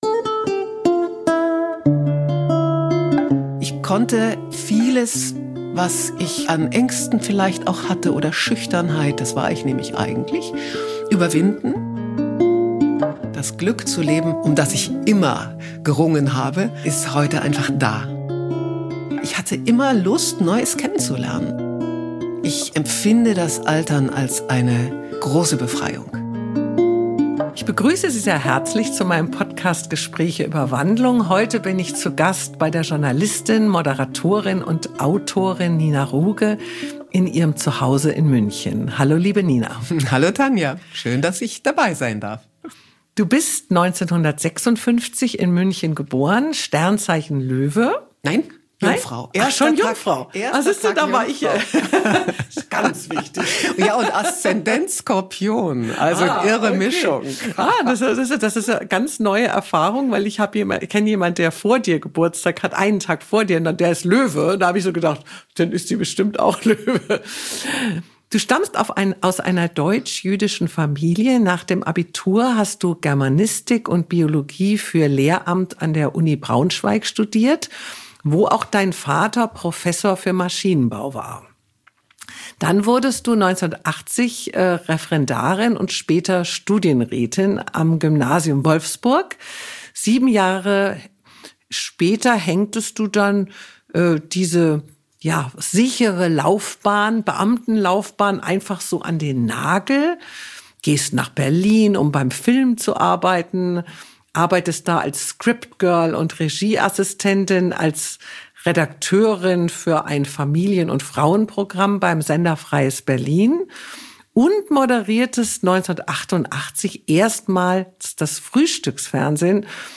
Herausgekommen sind Unterhaltungen, die charmant und schonungslos, emphatisch und tröstlich, aber nie indiskret oder voyeuristisch sind. Intime Gespräche, bei denen man als Zuhörer das Gefühl hat, mit am Tisch zu sitzen, ohne zu stören.